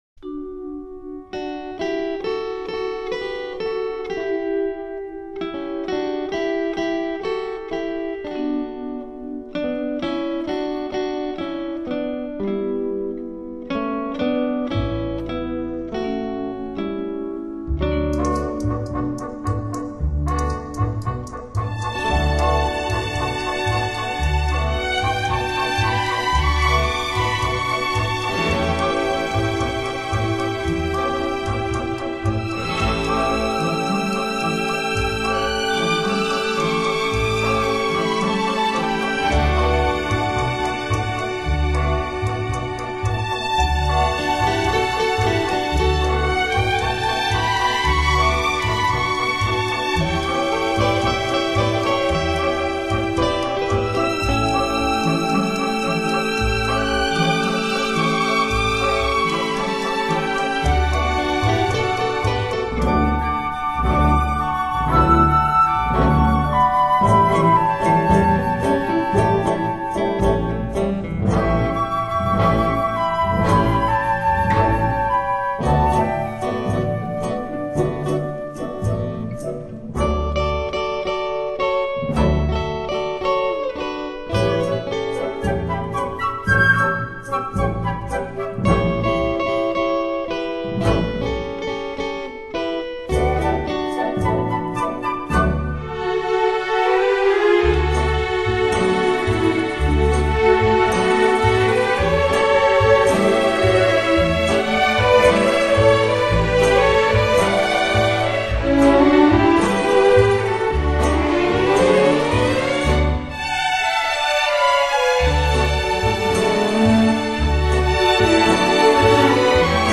在这里，他巧妙地依靠管弦乐队中的弦乐器，找到了一种富有特殊色彩的音响，这种富有特色的弦乐演奏，使他的音乐流传世界各地。